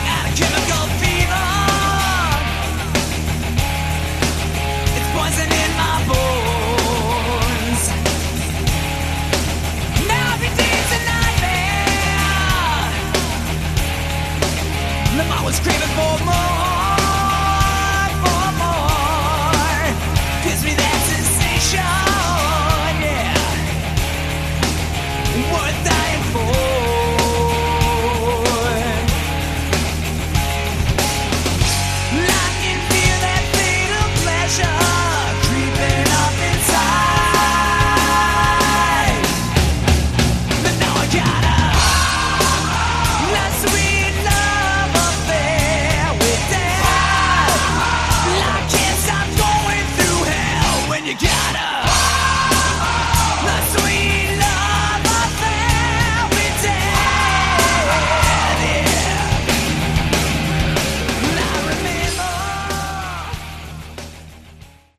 Category: Hard Rock
drums, backing vocals
lead vocals, guitar
bass guitar, backing vocals
This is good, solid hard rock with catchy choruses.